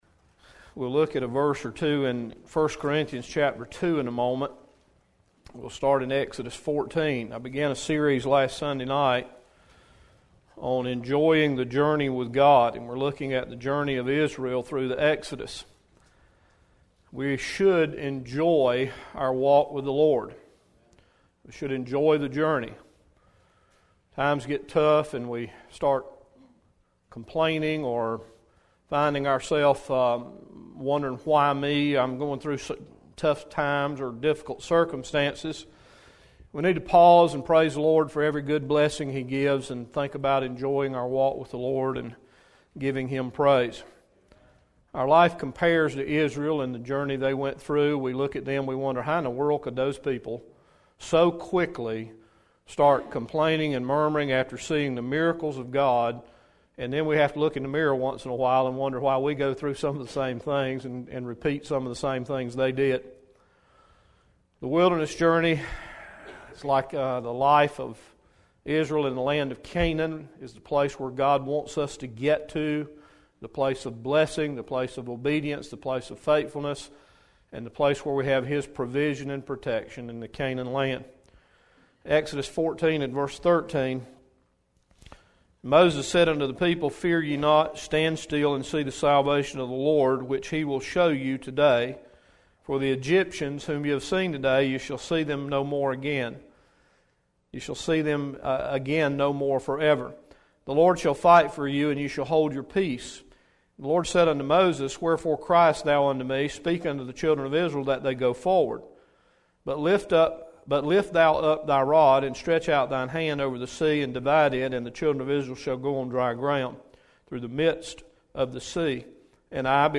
3-17-13-PM-Servicemessage.mp3